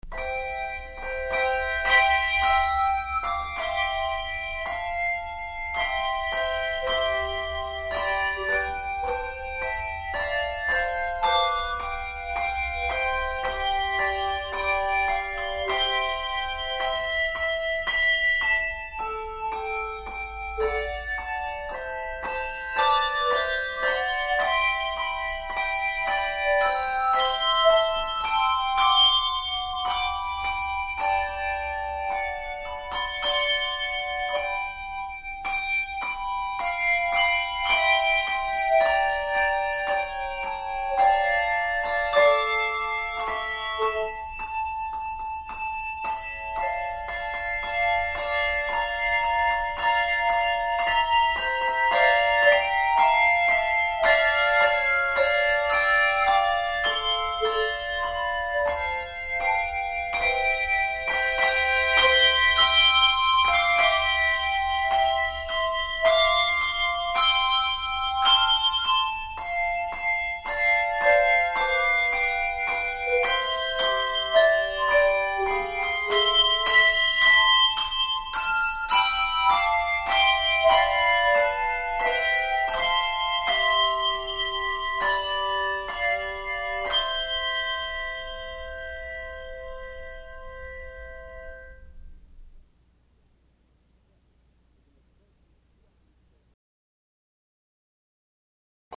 Handbell Quartet
Genre Sacred